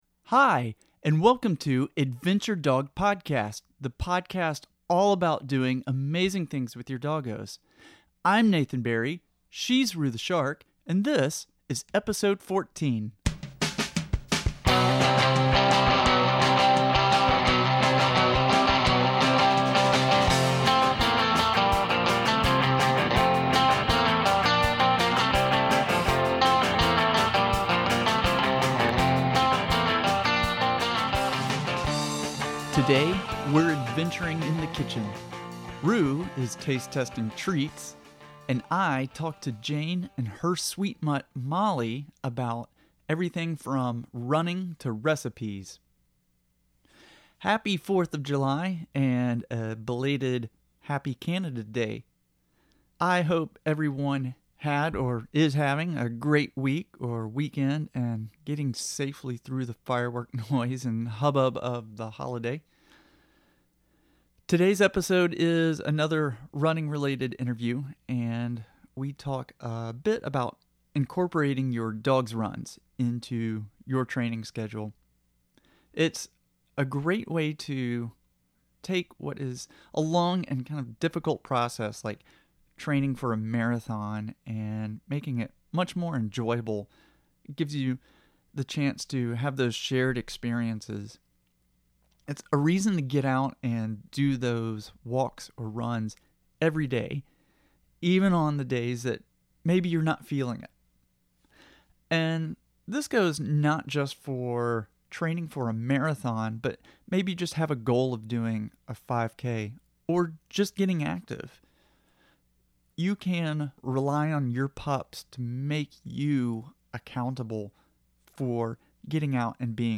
Today’s interview